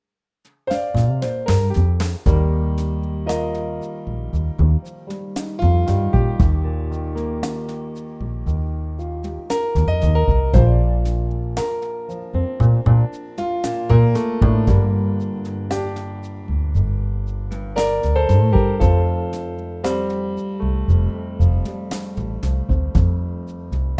Original Key